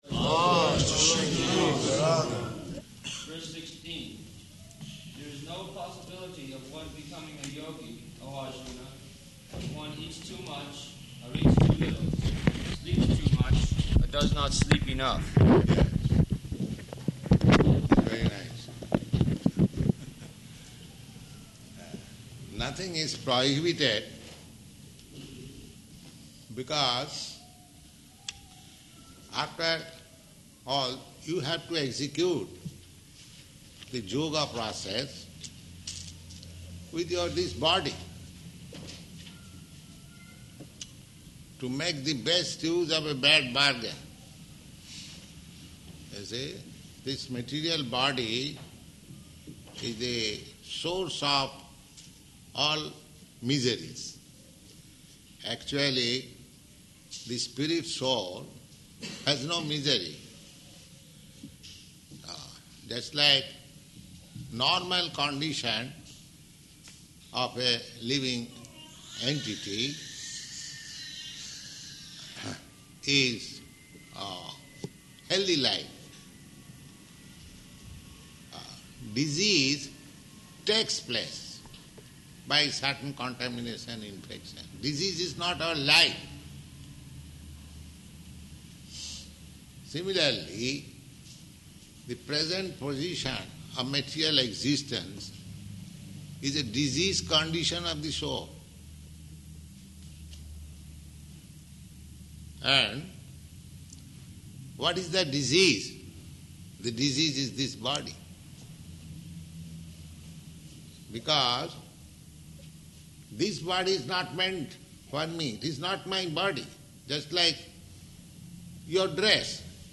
Bhagavad-gītā 6.16–24 [Sāṅkhya Yoga System] --:-- --:-- Type: Bhagavad-gita Dated: February 17th 1969 Location: Los Angeles Audio file: 690217BG-LOS_ANGELES.mp3 Devotees: All glories to Śrī Guru and Gaurāṅga.